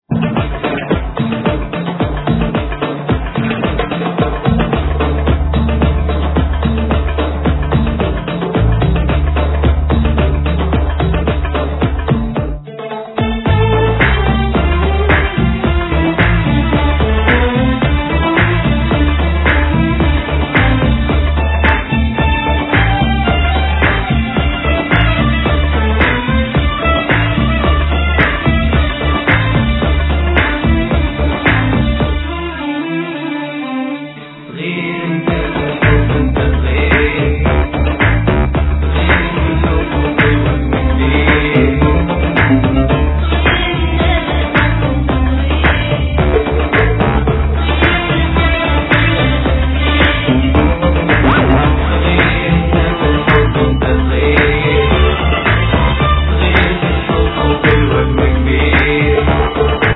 Voclas, Oud, Darbukka
Violin
Keyboards
Background Vocals
Bass
Drums